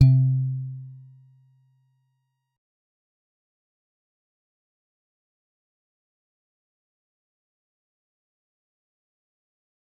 G_Musicbox-C3-mf.wav